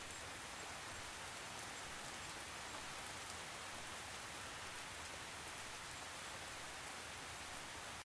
rain_indoors.ogg